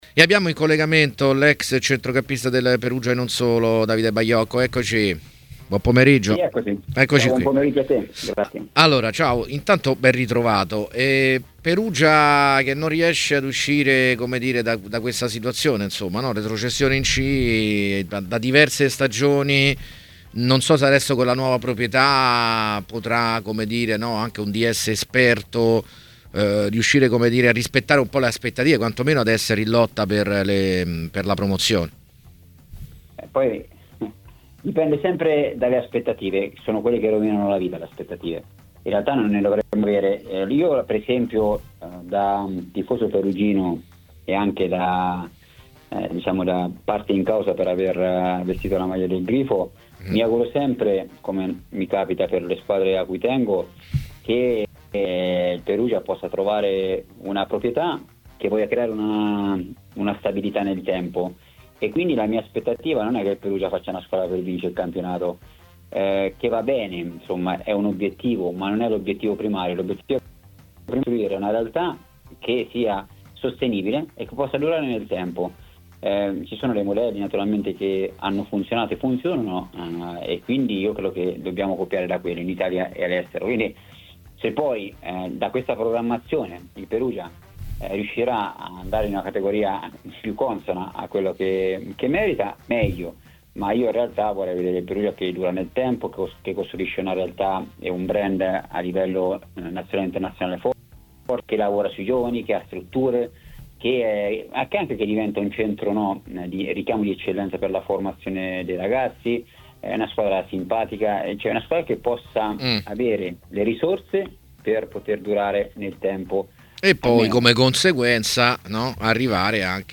Le Interviste
A intervenire a 'Calciomercato e Ritiri', trasmissione di Tmw Radio , è stato l'ex attaccante Francesco Baiano .